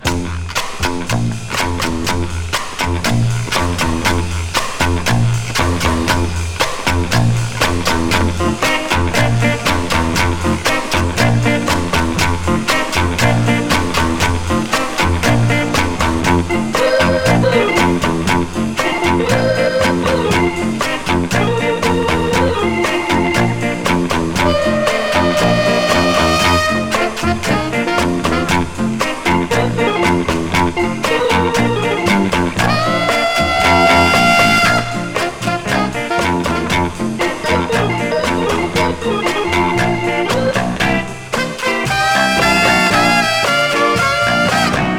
Jazz, Rock, Pop, Lounge　USA　12inchレコード　33rpm　Mono